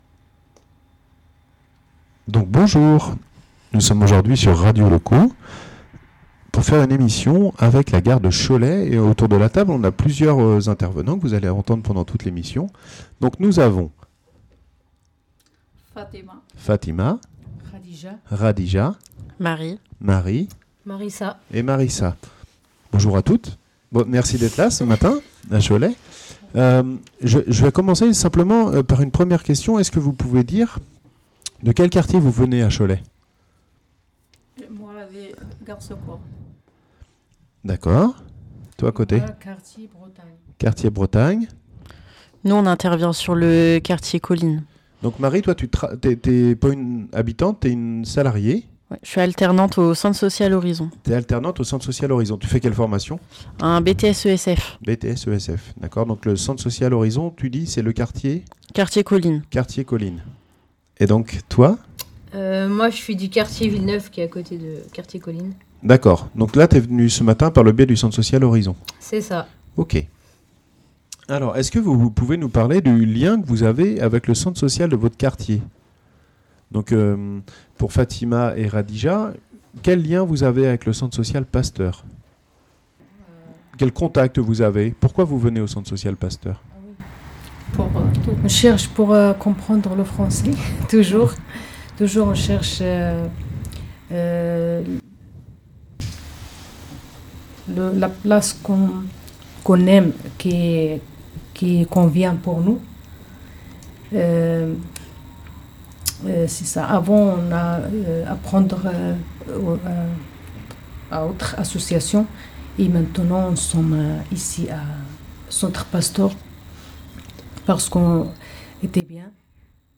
RADIO LOCO AU CENTRE SOCIAL PASTEUR A CHOLET
Retrouvez ici l'émission de Radio Loco avec les membres de la locomotive à Cholet. Des témoignages sur ce qui se vit au quotidien, au plus près des habitants.